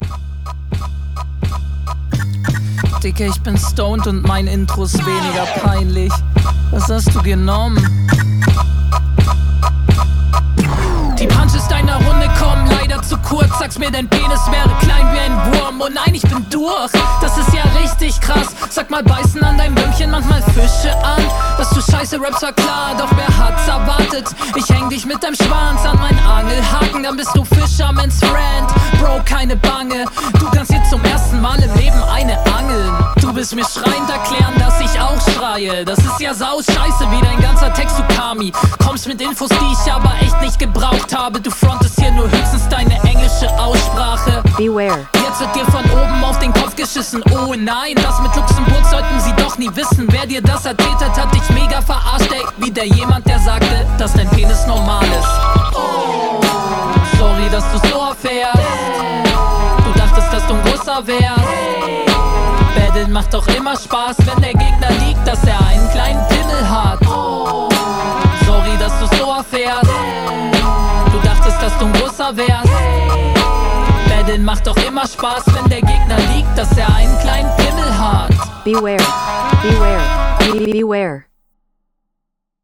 Kommst ähnlich gut wie dein Gegner auf dem Beat.